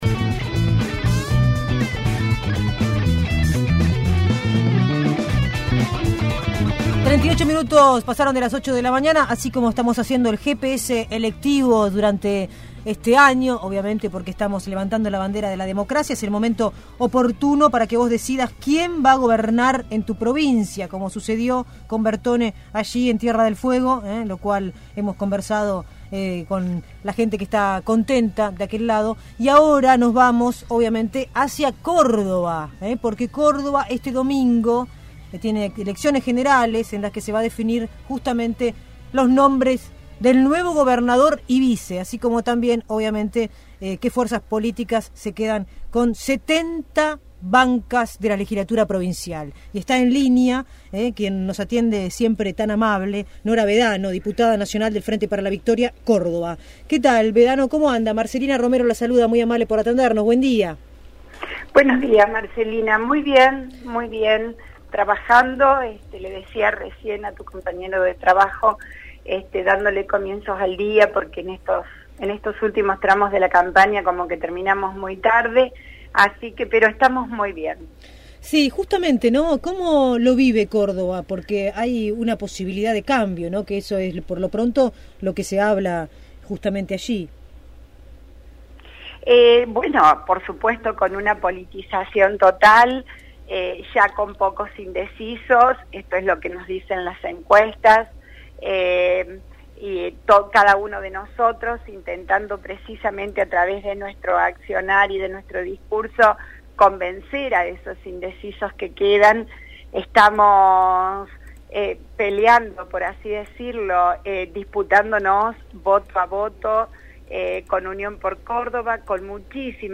La Diputada Nacional por Córdoba, del Frente Para la Victoria